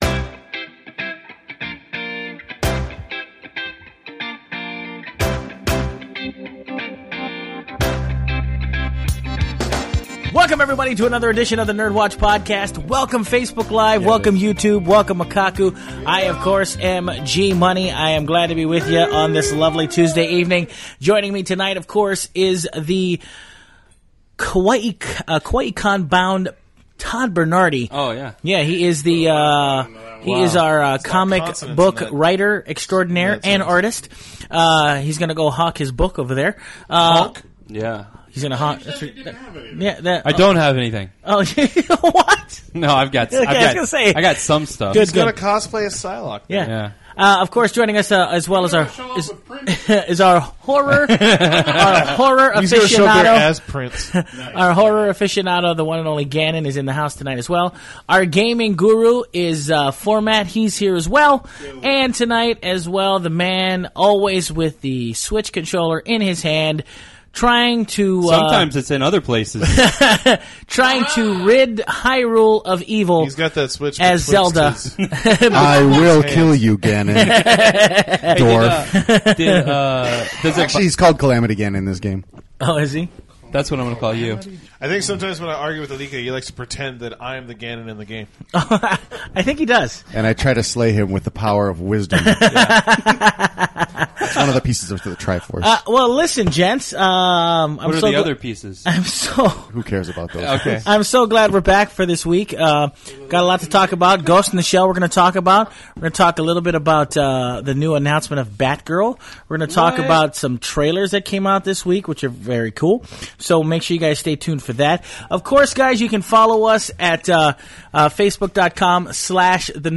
All this recorded live at Maui Comics & Collectibles.